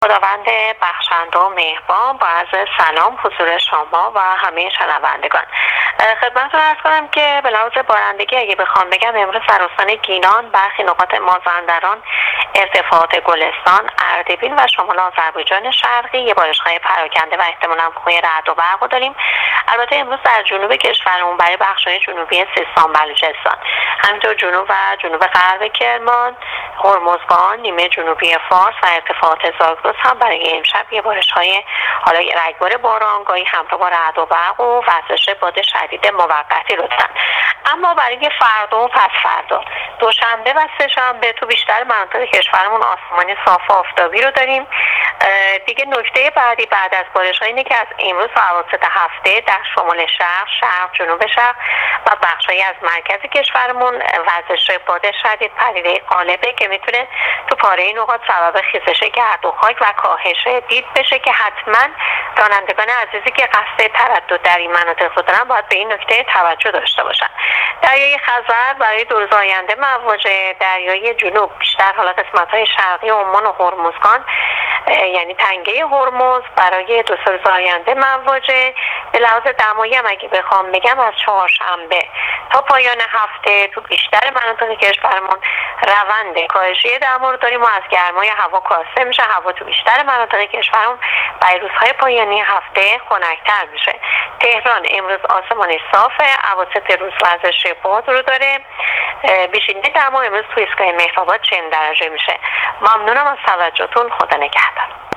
کارشناس سازمان هواشناسی کشور در گفت‌وگو با رادیو اینترنتی وزارت راه و شهرسازی، آخرین وضعیت آب‌و‌هوای کشور را تشریح کرد.
گزارش رادیو اینترنتی از آخرین وضعیت آب‌‌و‌‌‌هوای سوم مرداد